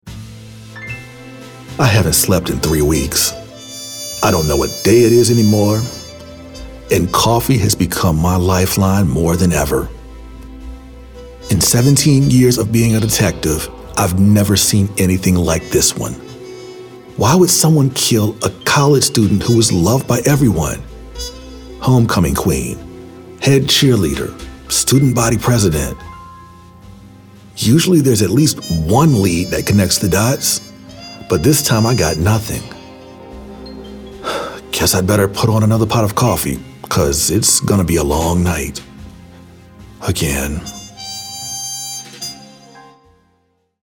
Smooth, warm, and informative - perfect for authentic sounding copy.
authoritative, character, confessional, conversational, gravelly, nostalgic, real, retro, smooth, storyteller